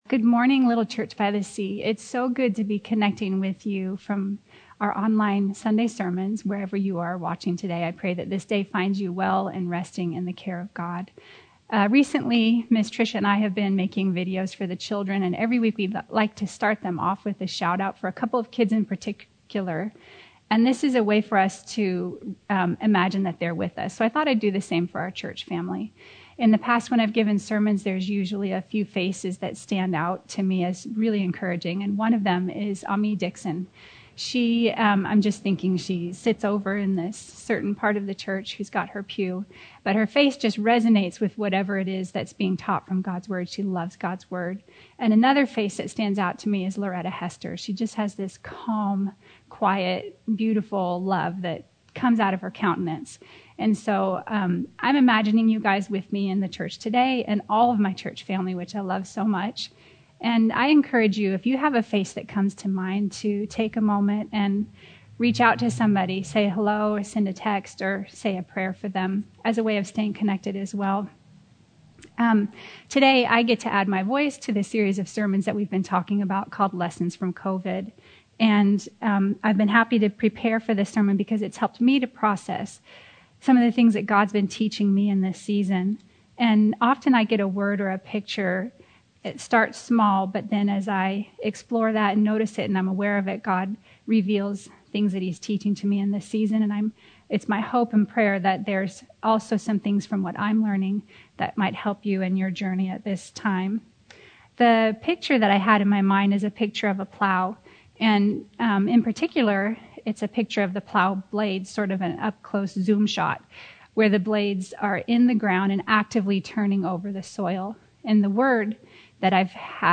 Sermons from Quarantine: The Divine Farmer